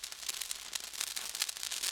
fuse.wav